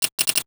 NOTIFICATION_Glass_09_mono.wav